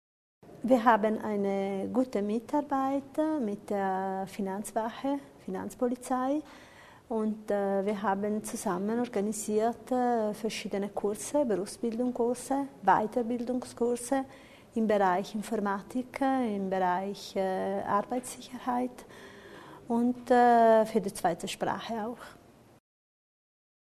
Landesrätin Repetto über die Zusammenarbeit mit der Finanzwache
(LPA) Die Zusammenarbeit zwischen der Landesabteilung für italienische Berufsbildung und den Mitgliedern der Finanzwache wird fortgesetzt, erklärte heute die zuständige Landesrätin Barbara Repetto bei einer Pressekonferenz. Vor allem die Deutschkurse sollen ausgebaut werden.